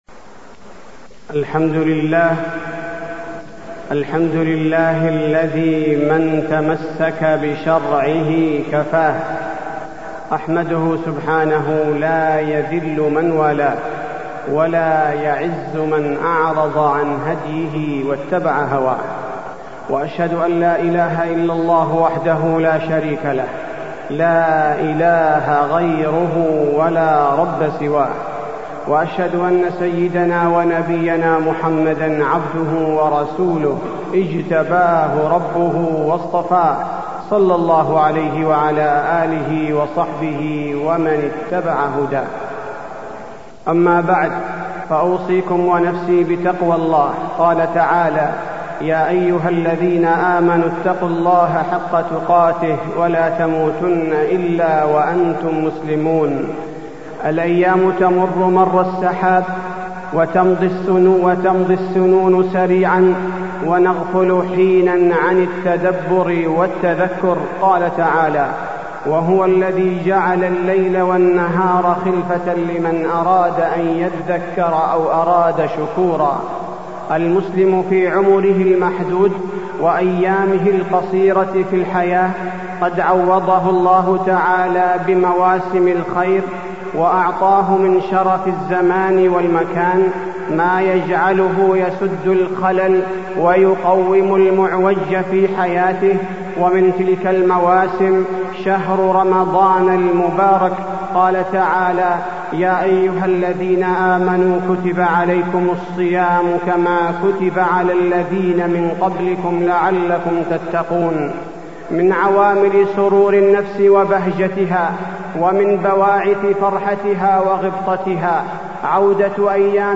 دروس رمضان
تاريخ النشر ٨ رمضان ١٤٢٥ هـ المكان: المسجد النبوي الشيخ: فضيلة الشيخ عبدالباري الثبيتي فضيلة الشيخ عبدالباري الثبيتي دروس رمضان